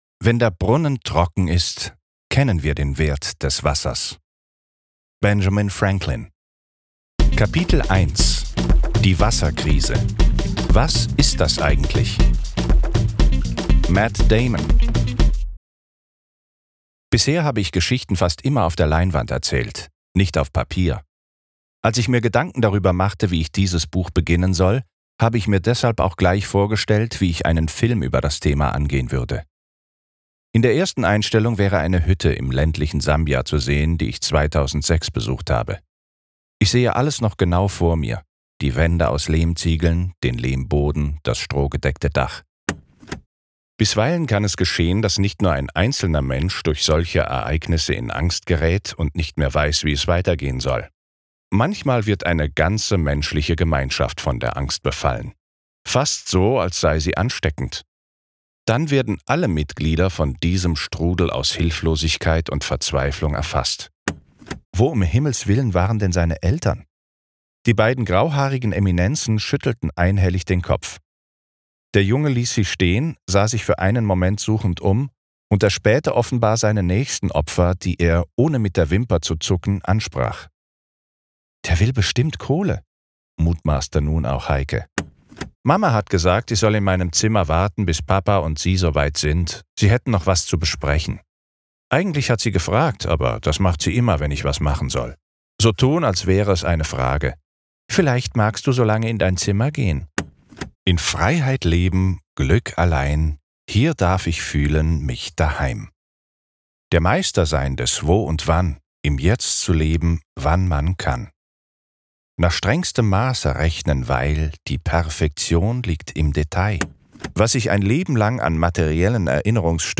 markant, dunkel, sonor, souverän, sehr variabel, plakativ
Mittel plus (35-65)
Trailer Hörbuch
Audiobook (Hörbuch)